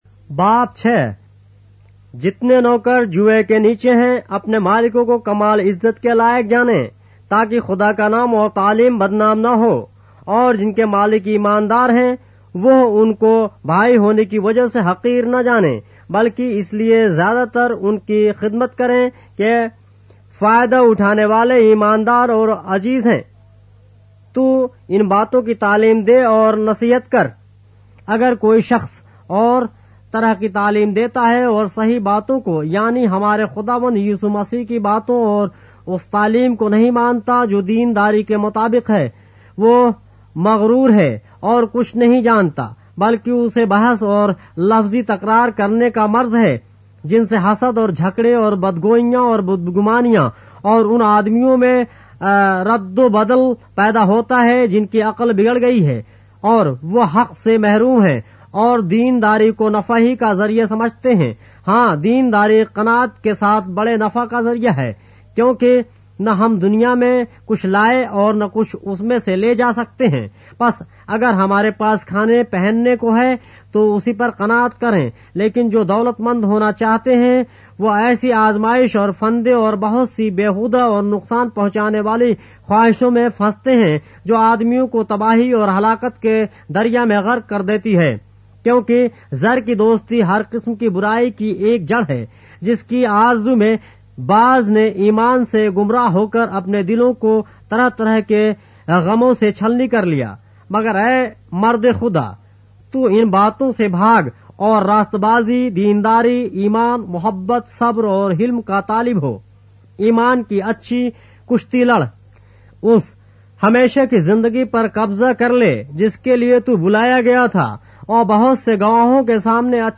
اردو بائبل کے باب - آڈیو روایت کے ساتھ - 1 Timothy, chapter 6 of the Holy Bible in Urdu